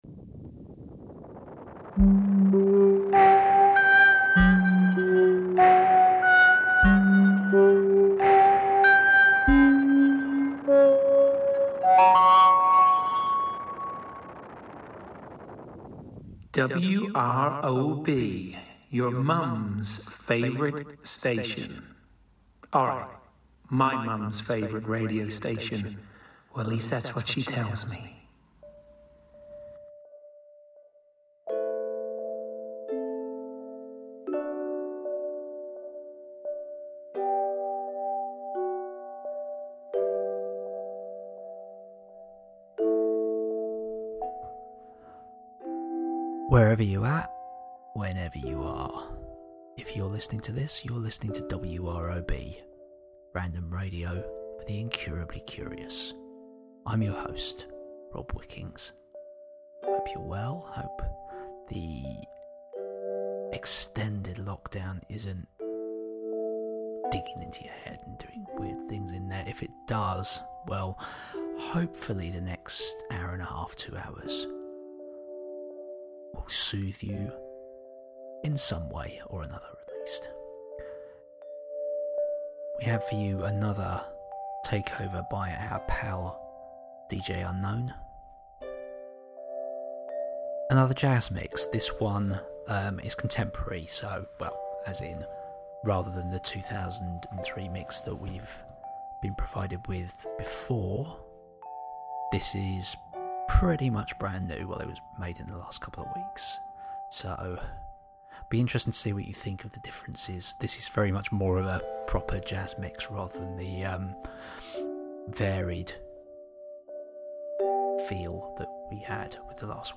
Another hit of prime jazz goodness